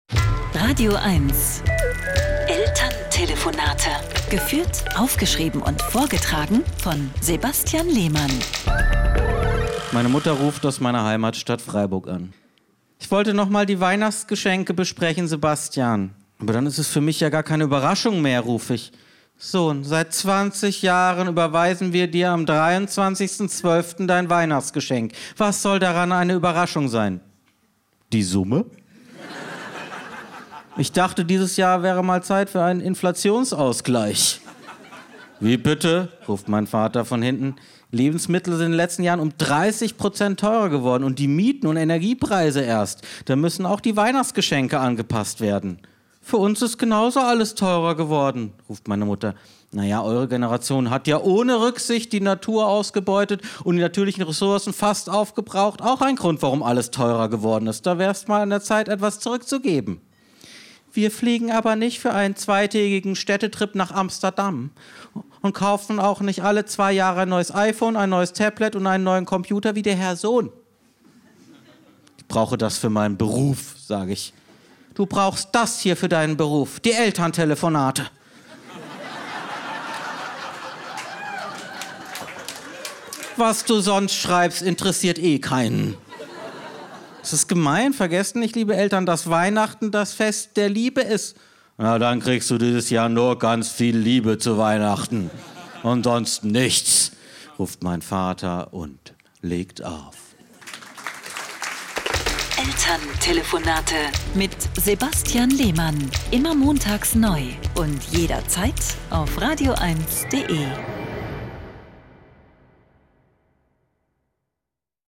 Deswegen muss er oft mit seinen Eltern in der badischen Provinz telefonieren.
Comedy